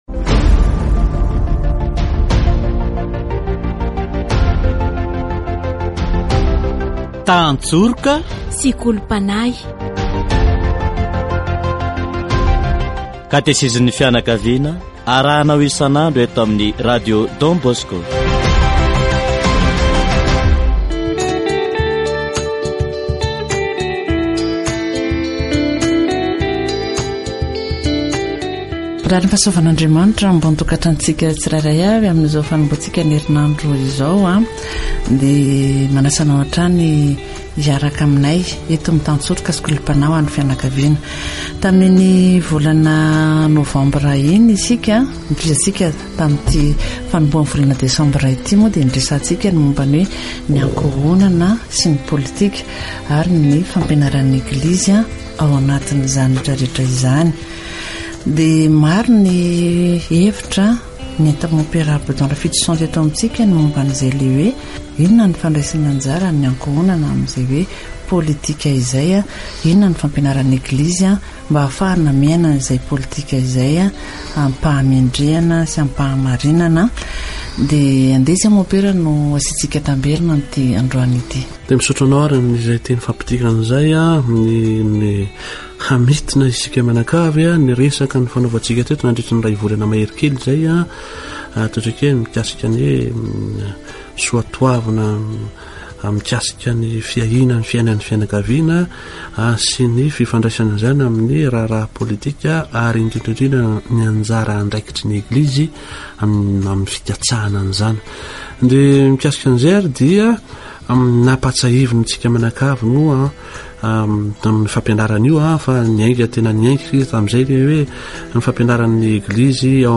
Catechesis on the Church, the family and politics